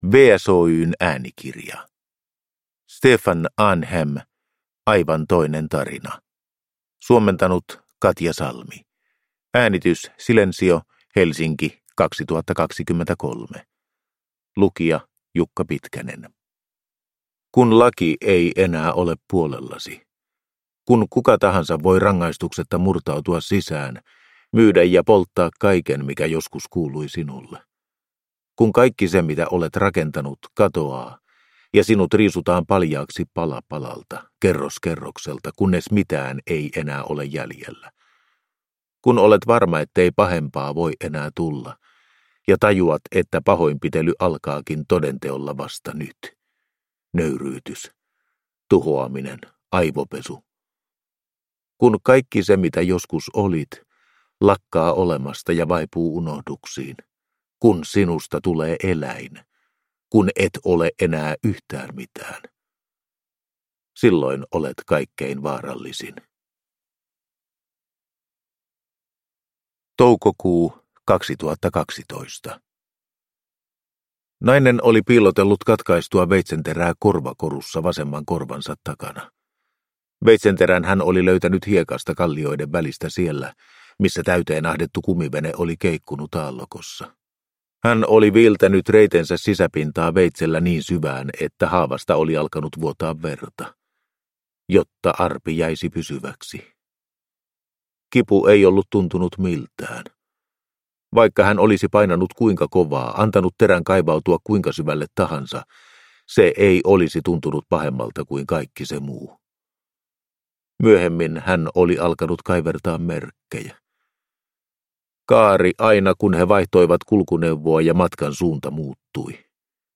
Aivan toinen tarina – Ljudbok